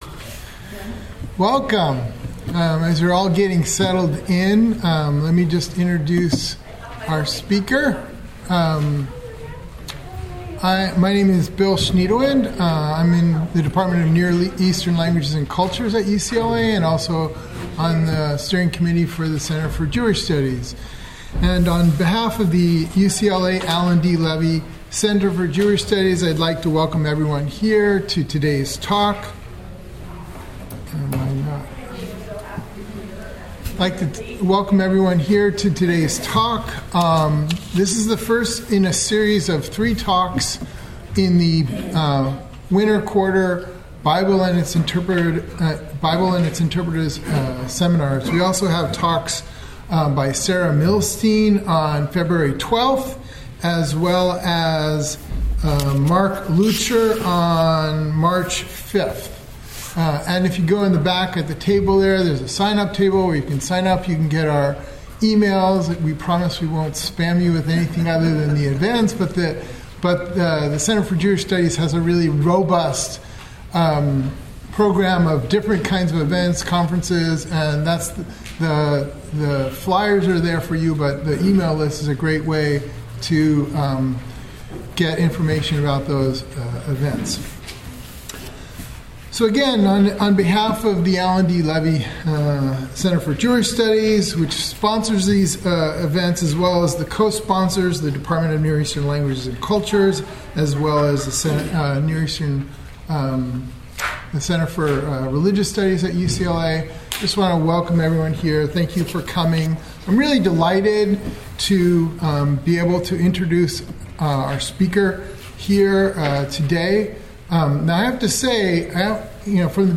This talk examines the effect of the sixth century BCE deportations to Babylonia on Israelite identity. Paying close attention to the prophetic book of Ezekiel, whose community was deported from Jerusalem to Babylonia by Nebuchadnezzar, it explores how the experience of forced migration changed the way the people talked about themselves, their past, and their homeland. To help make sense of these changes, it places Ezekiel’s reactions in conversation with the responses of more recent forced migrants to similar experiences.